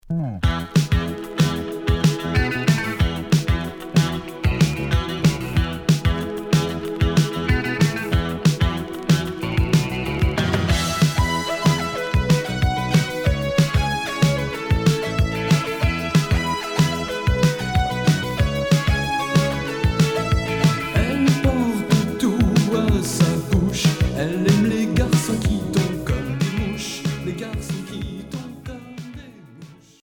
Rock new wave Deuxième 45t retour à l'accueil